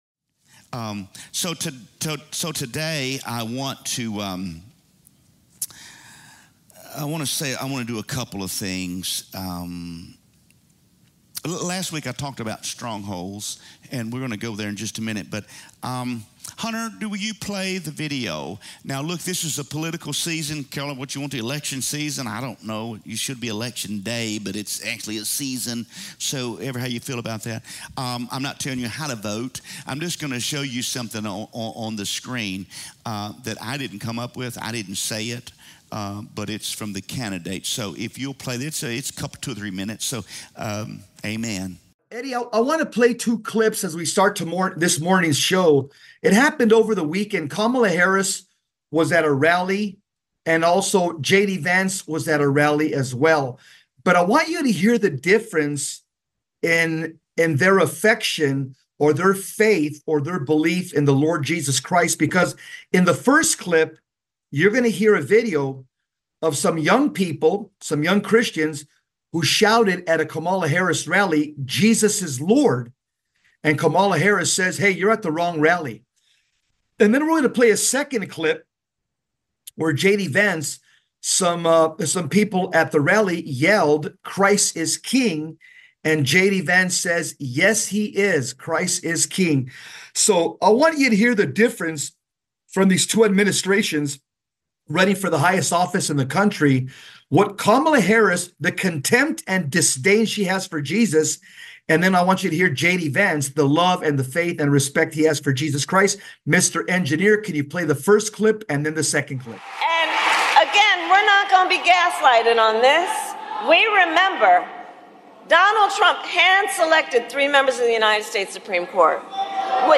This week, we’re diving deep into spiritual awareness with our sermon “Knowing the Enemy.”